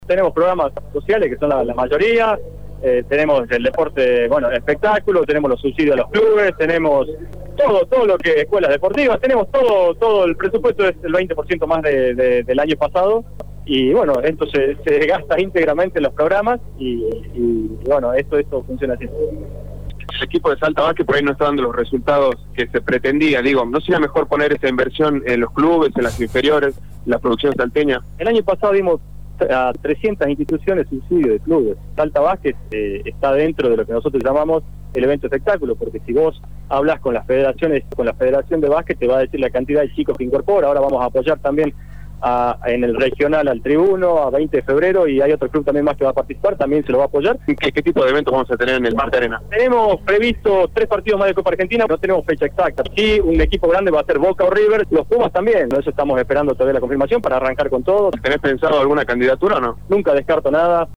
El Secretario de Deportes de la Provincia Sergio Plaza, en diálogo con Radio Dínamo se refirió a los programas de su gestión y una probable candidatura.